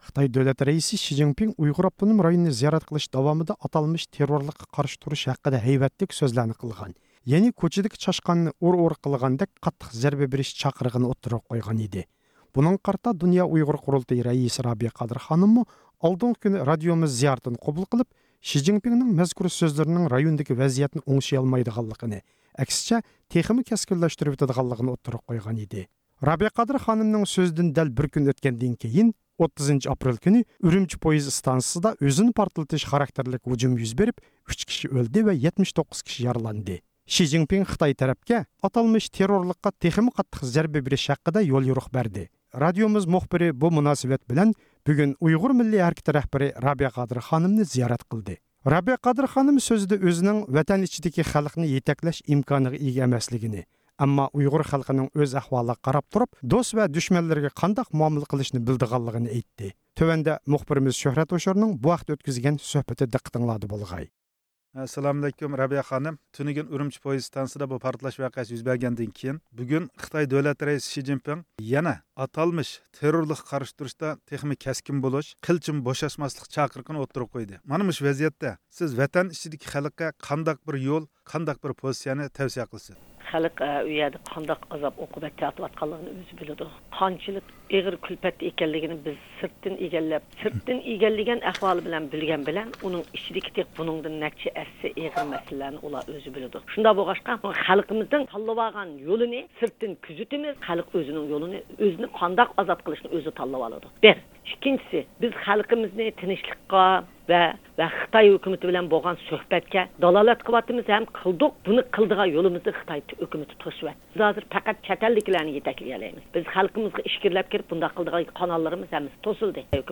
بىز بۈگۈن بۇ مۇناسىۋەت بىلەن، ئۇيغۇر مىللىي ھەرىكىتى رەھبىرى رابىيە قادىر خانىمنى زىيارەت قىلدۇق. رابىيە قادىر خانىم ئۆزىنىڭ نۆۋەتتە ۋەتەن ئىچىدىكى خەلقنى يېتەكلەش ئىمكانىغا ئىگە ئەمەسلىكىنى، ئەمما ئۇيغۇر خەلقىنىڭ ئۆز ئەھۋالىغا قاراپ تۇرۇپ دوست ۋە دۈشمەنلىرىگە قانداق مۇئامىلە قىلىشنى بىلىدىغانلىقىنى ئېيتتى.